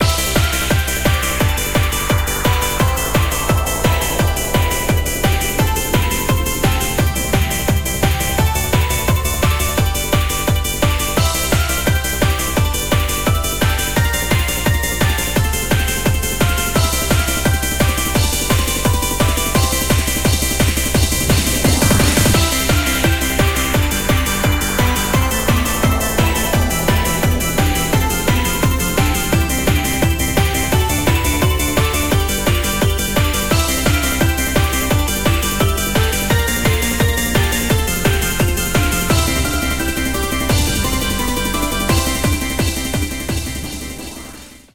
Happy Hardcore Remix Demo)file